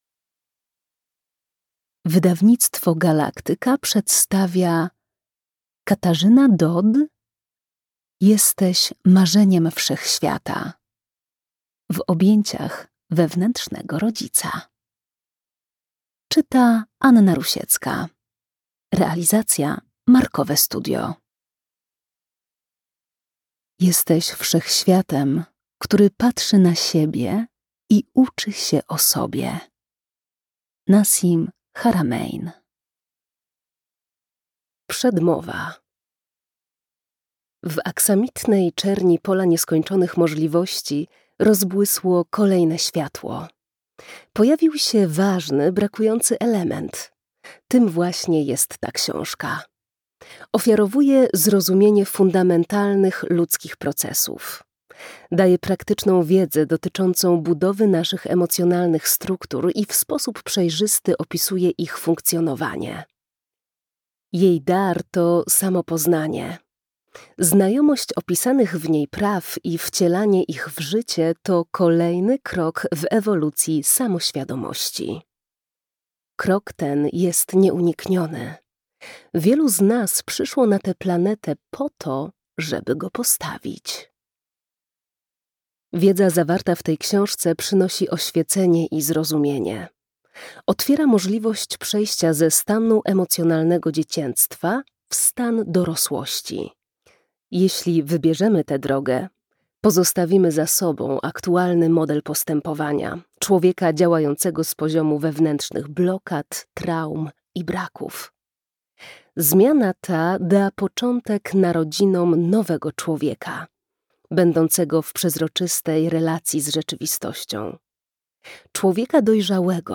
fragment książki: